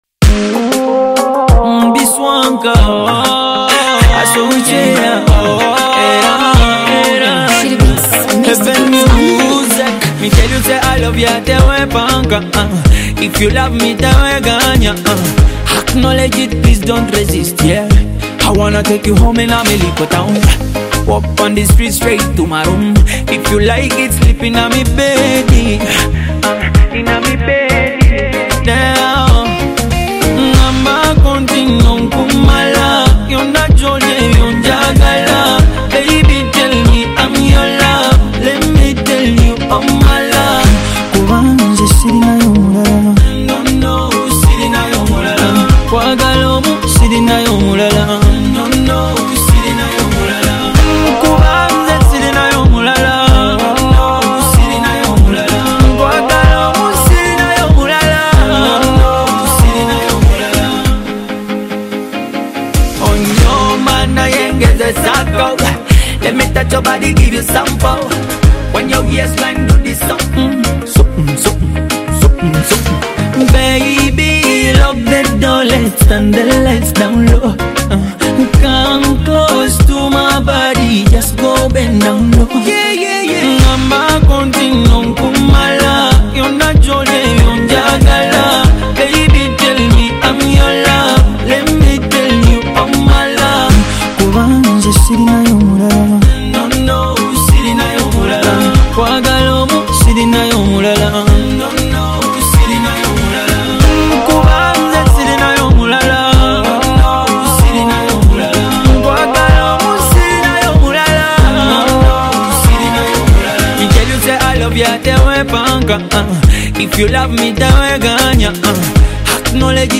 Afro-Pop
blending smooth vocals with upbeat, contemporary production.